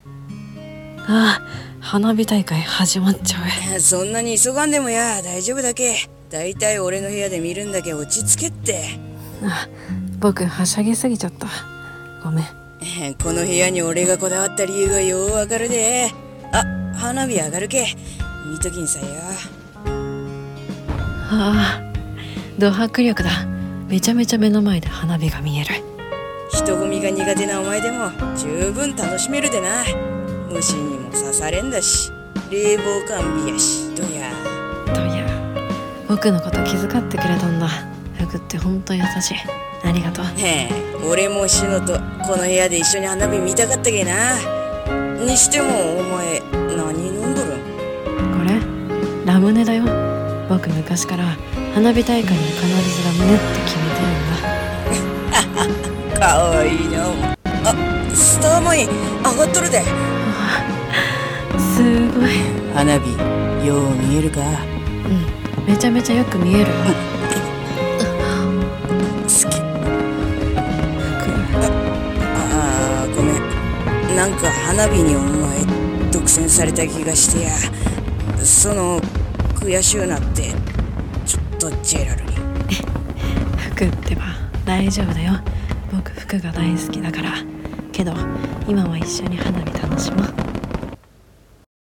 【BL声劇台本】花火大会のvip席